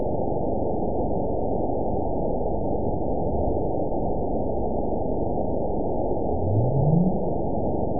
event 917123 date 03/20/23 time 21:54:22 GMT (2 years, 1 month ago) score 9.63 location TSS-AB01 detected by nrw target species NRW annotations +NRW Spectrogram: Frequency (kHz) vs. Time (s) audio not available .wav